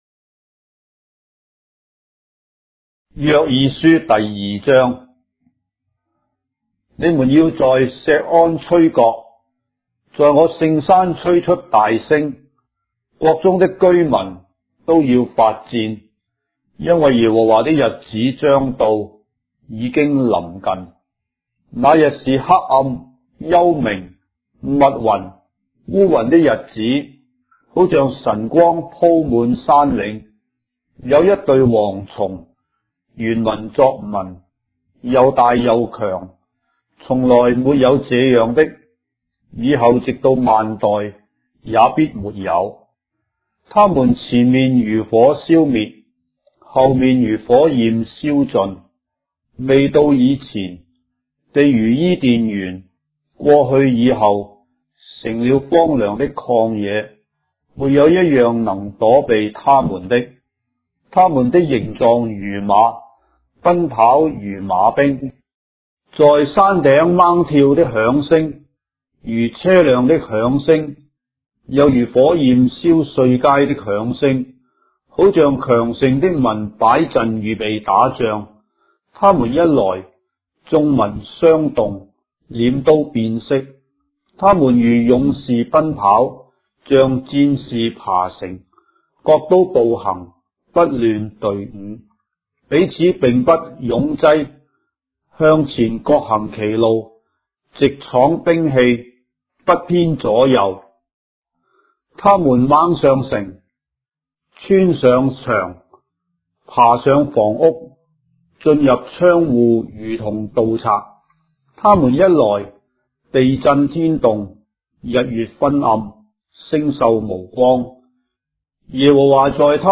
章的聖經在中國的語言，音頻旁白- Joel, chapter 2 of the Holy Bible in Traditional Chinese